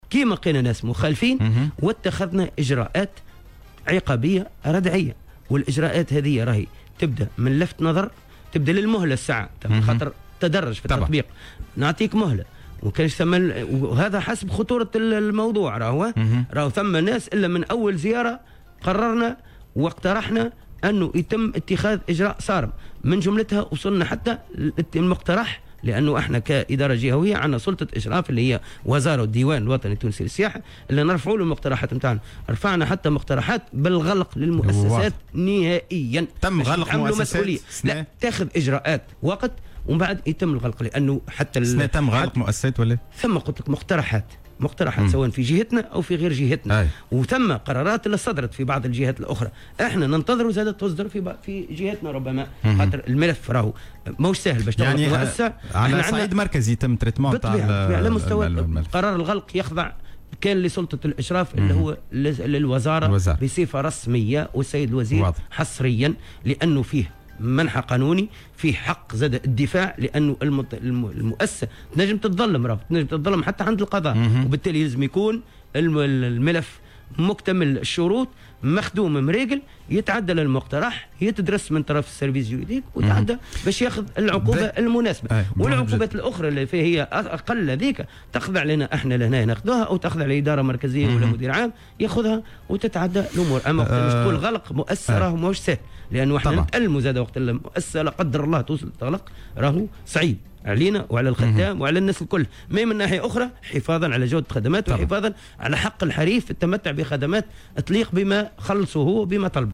وأضاف في مداخلة له اليوم على "الجوهرة أف أم" أن ذلك جاء اثر معاينة جملة من الإخلالات وسعيا للحفاظ على جودة الخدمات المقدمة ودفاعا عن حق الحريف.